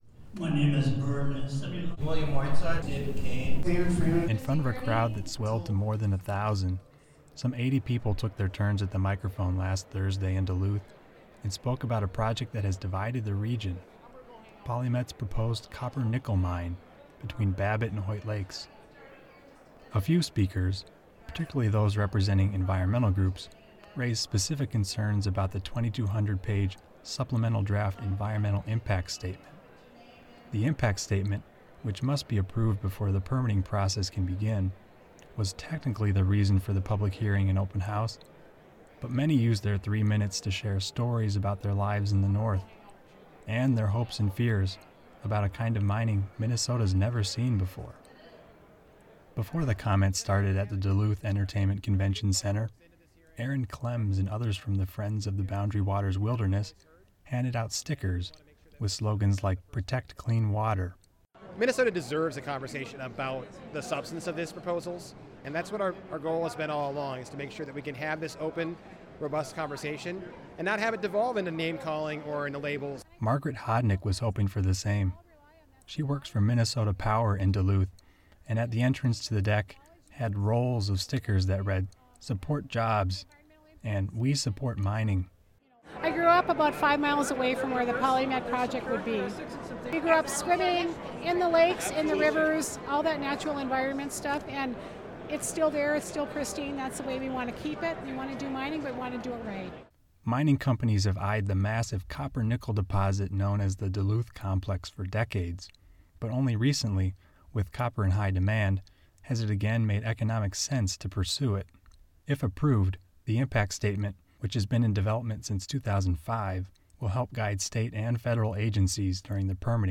Non-ferrous mining supporters, critics convene for Polymet's Duluth public meeting